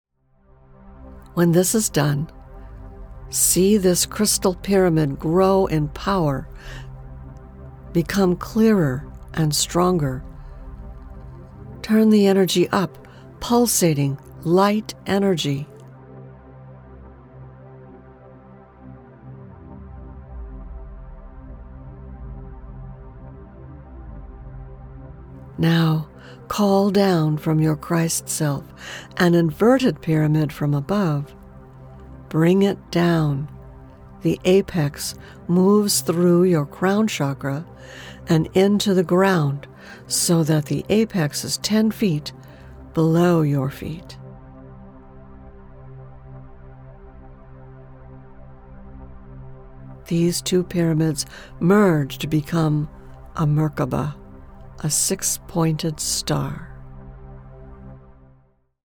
Guided Meditations Bundle 11 - 20
Includes mp3 track with voice and background sound, a track with voice only, and a pdf of the text from each meditation.